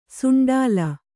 ♪ suṇḍālu